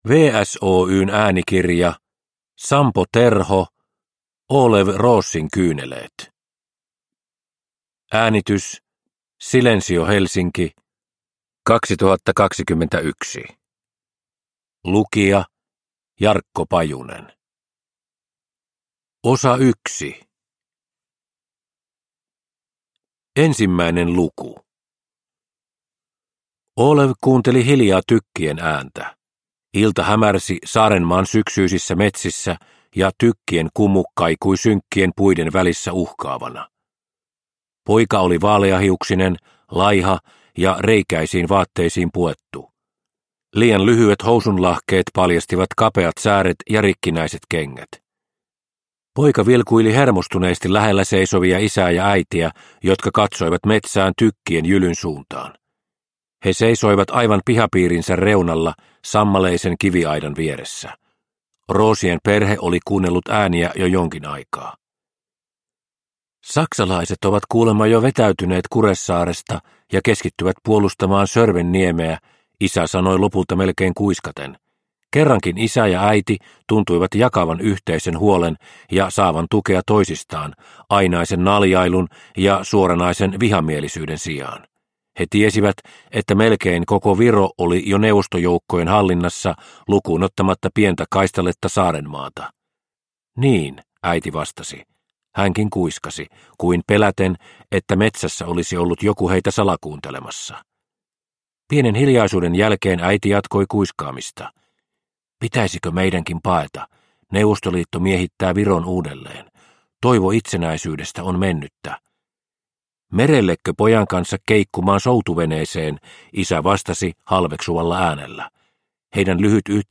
Olev Roosin kyyneleet – Ljudbok – Laddas ner